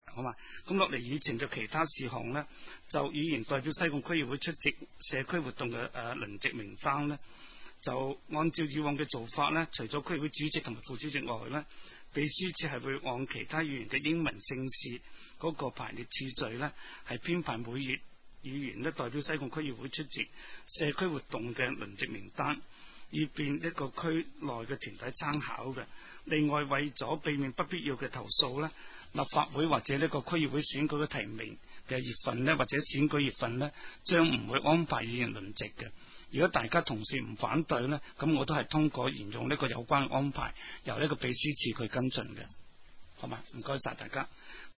西貢区议会第一次会议
三楼会议室